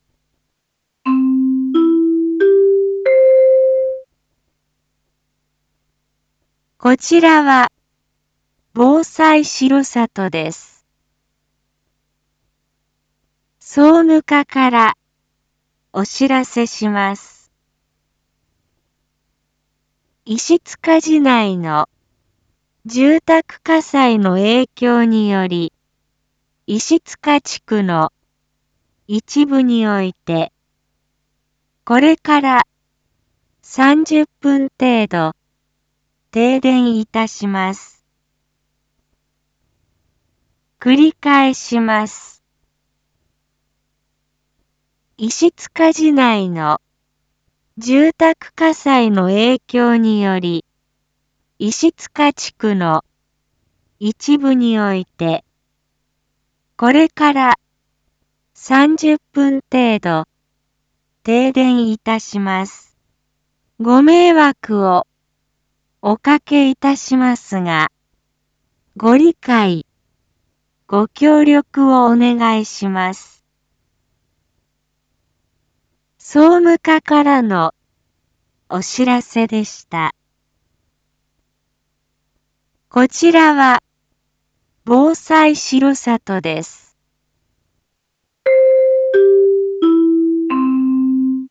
一般放送情報
Back Home 一般放送情報 音声放送 再生 一般放送情報 登録日時：2022-02-04 13:05:33 タイトル：R4.2.4 停電について インフォメーション：こちらは防災しろさとです。